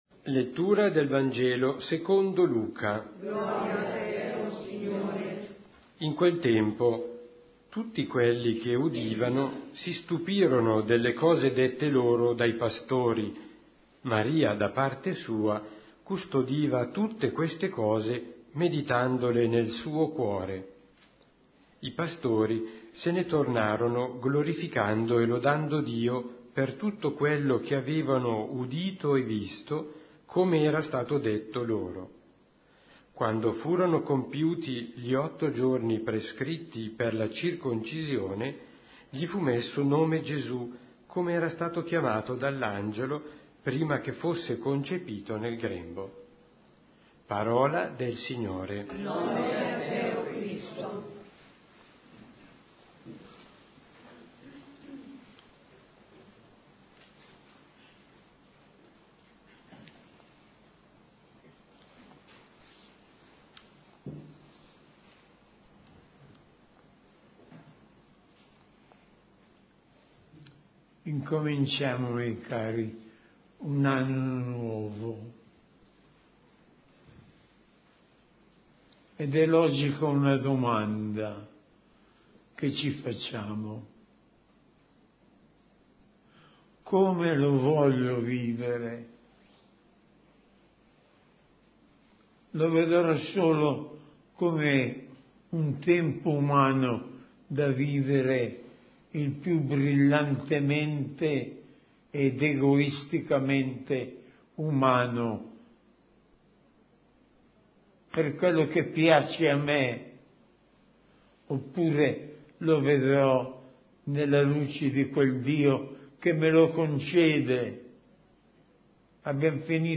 Omelia S.Messa del giorno